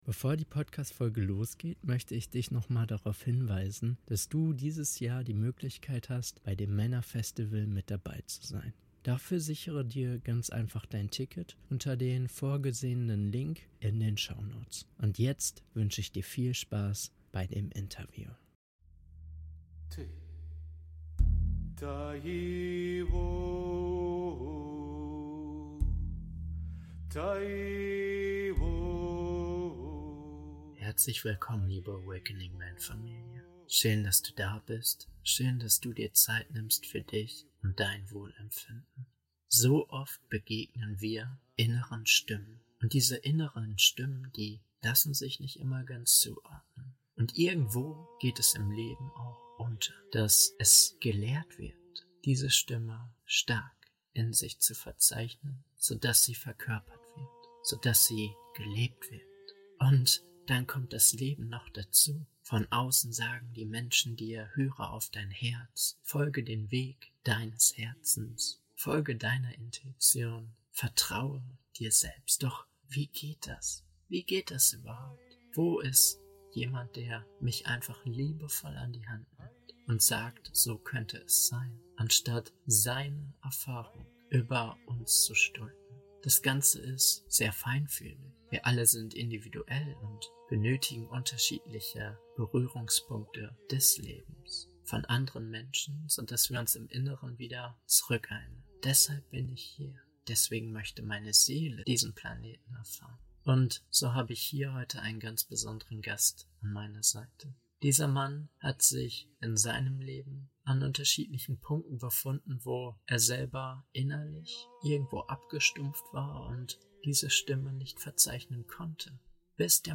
Fühlen als Wegweiser - Interview